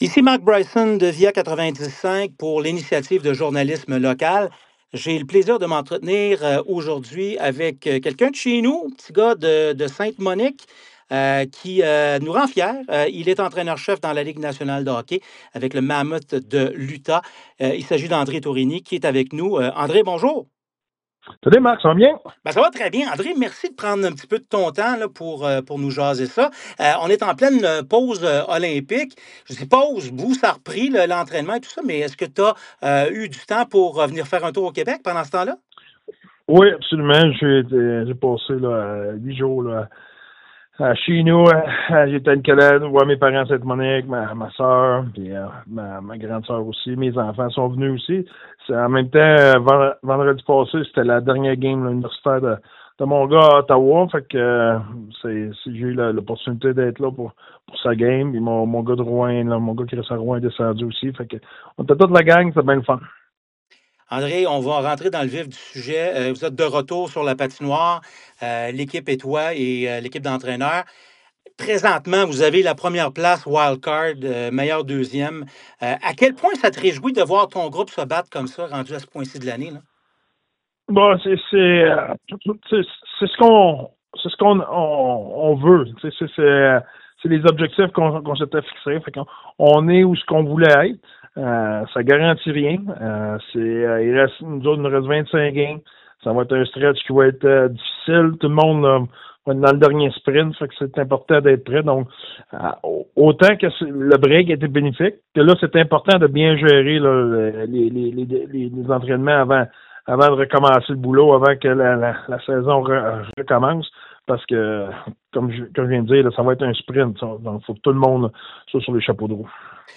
Entrevue audio avec André Tourigny | Une pause familiale pour le coach pendant la pause olympique
VIA 90.5 FM a profité de la pause olympique dans la Ligue nationale de hockey (LNH) pour discuter avec l’entraîneur-chef originaire de Sainte-Monique, André Tourigny.
entrevue-Andre-Tourigny-Mammoth-Utah.mp3